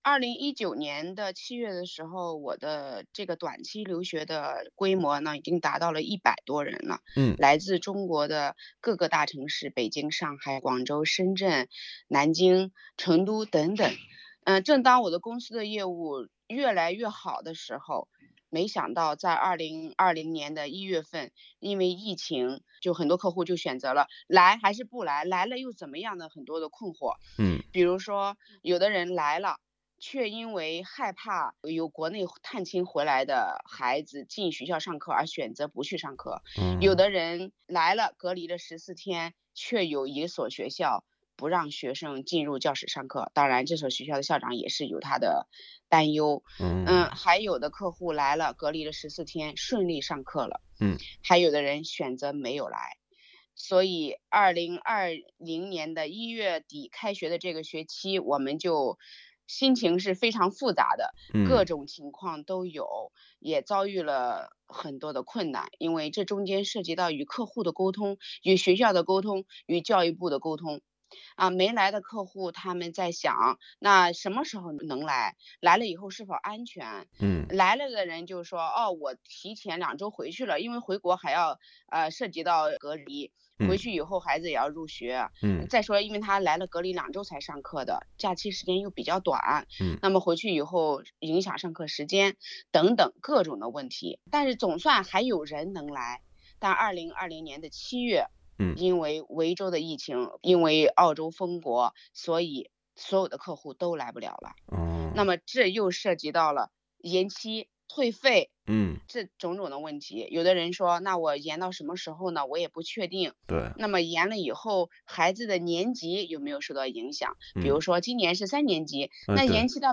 （欢迎点击图片音频，收听完整采访。）